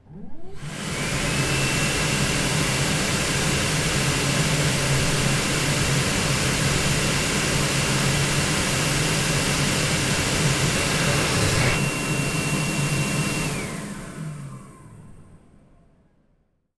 トイレのエアータオル２
hand_dryer2.mp3